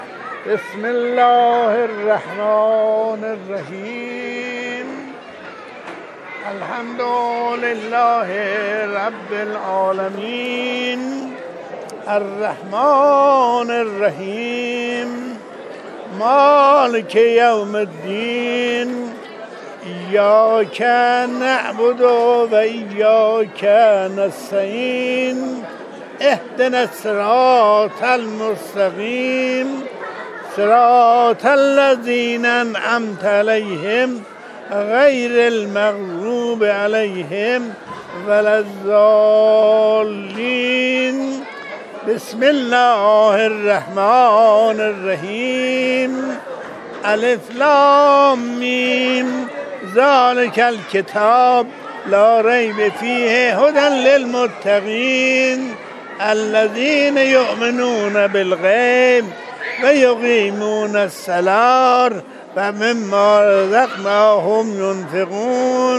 او ساده و بی‌آلایش قرآن می‌خواند.